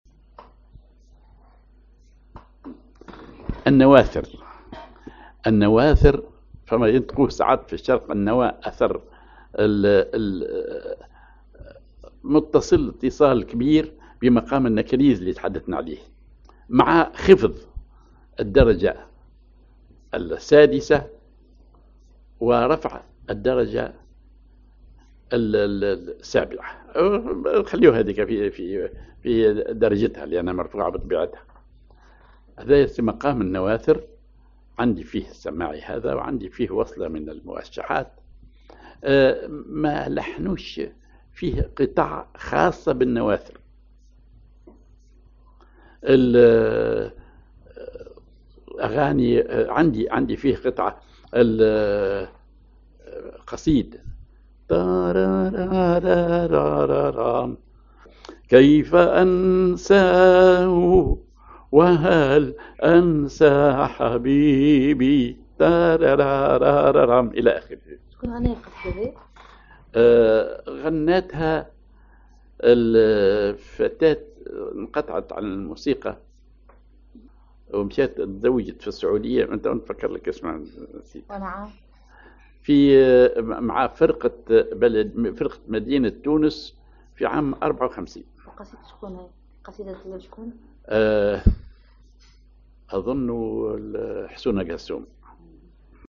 Maqam ar نواثر
Rhythm ID سماعي ثقيل
genre سماعي